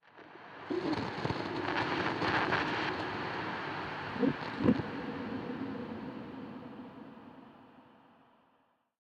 Minecraft Version Minecraft Version latest Latest Release | Latest Snapshot latest / assets / minecraft / sounds / ambient / nether / warped_forest / addition4.ogg Compare With Compare With Latest Release | Latest Snapshot